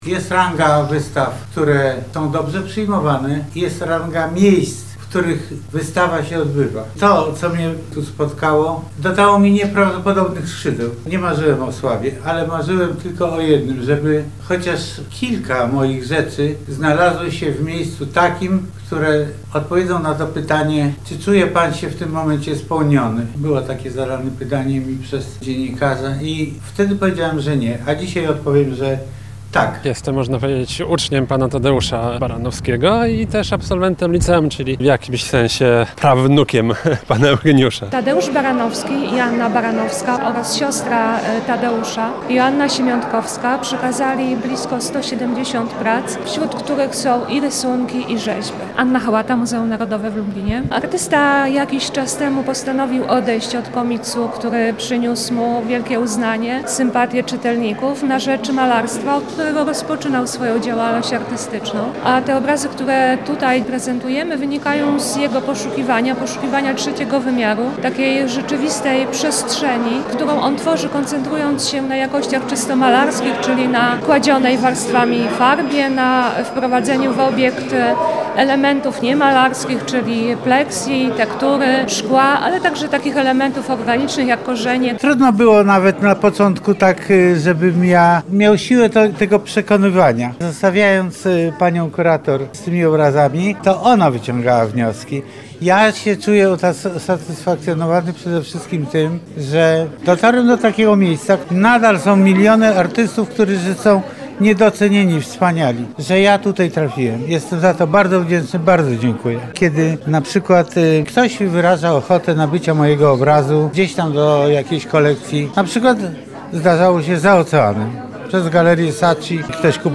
Kiedyś zadał mi je dziennikarz i wtedy powiedziałem, że nie, a dzisiaj odpowiem, że tak – mówił podczas wernisażu sam Tadeusz Baranowski.